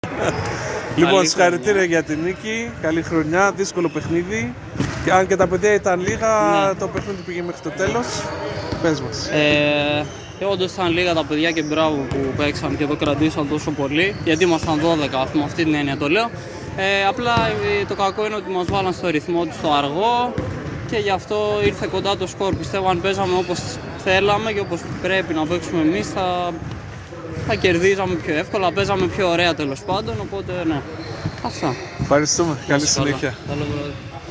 GAME INTERVIEWS
Παίκτης Vodafone